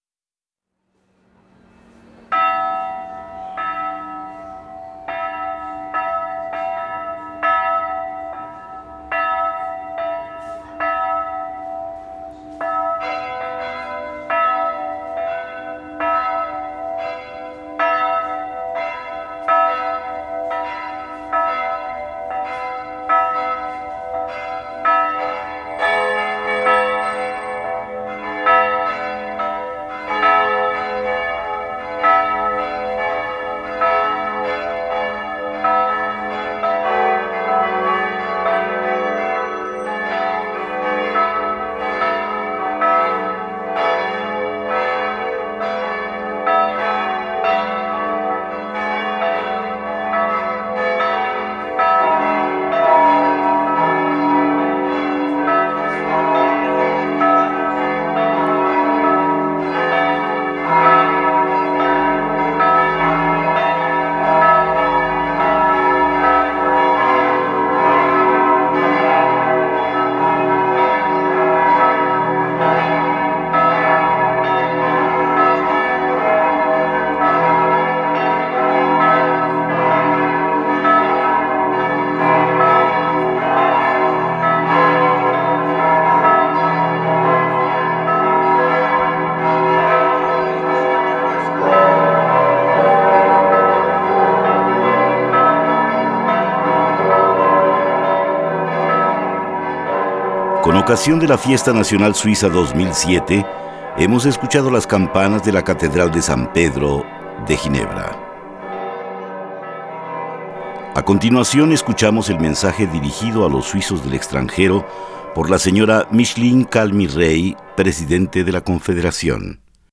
Alocución de la presidenta de la Confederación Micheline Calmy-Rey a los suizos en el exterior.